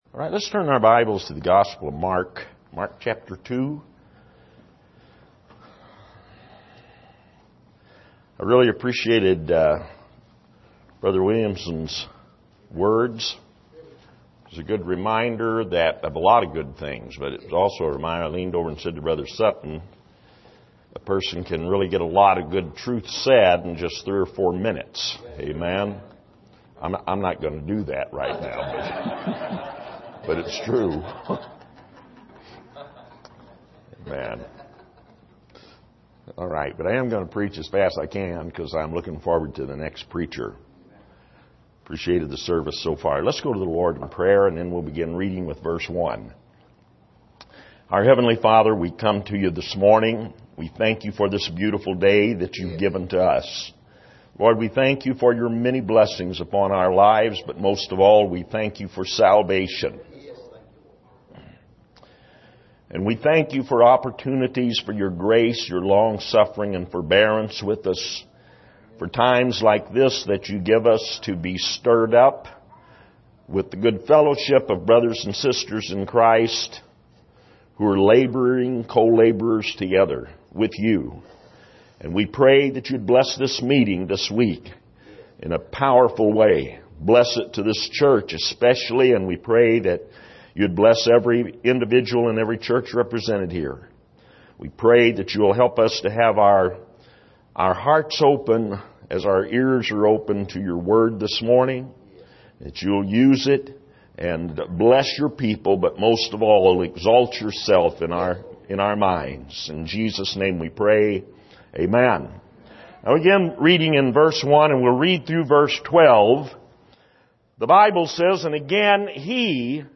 Passage: Mark 2:1-12 Service: Missions Conference